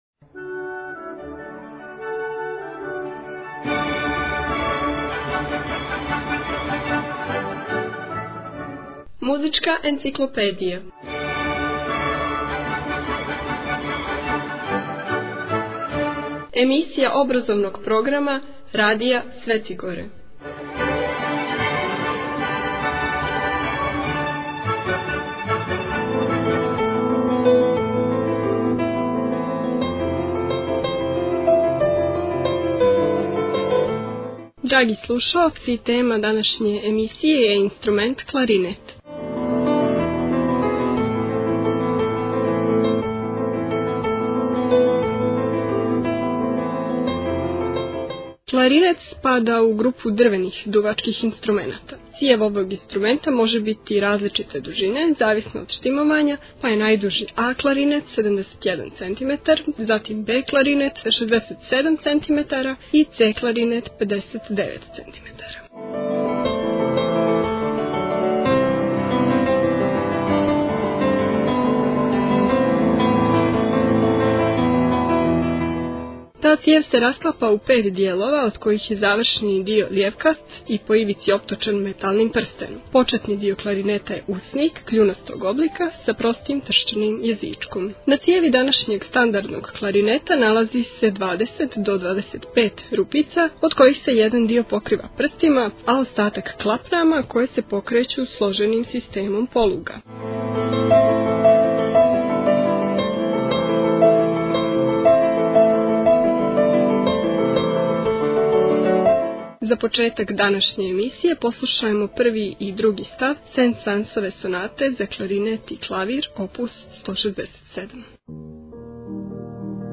Музичка енциклопедија CLXXIX Tagged: Музичка енциклопедија Download the file . 30:32 минута (4.37 МБ) У емисији "Музичка енциклопедија" можете чути инструмент кларинет и сазнати нешто више о њему.